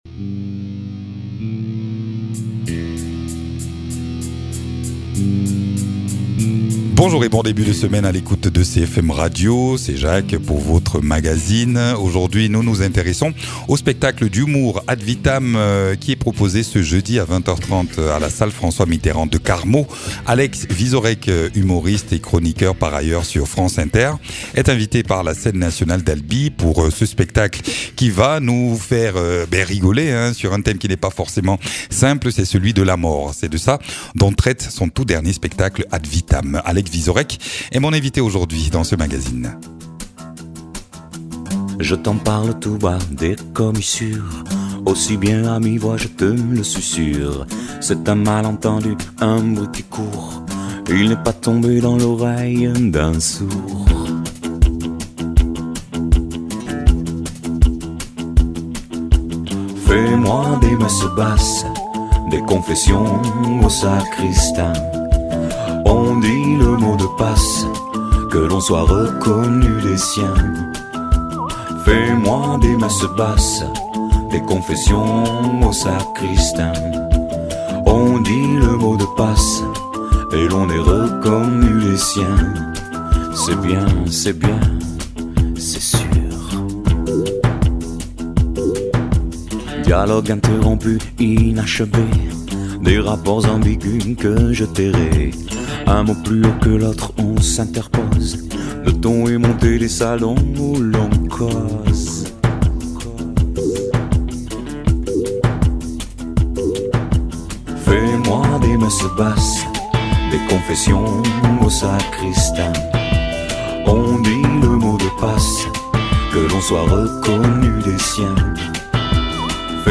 Invité(s) : Alex Vizorek, comédien-humoriste et chroniqueur.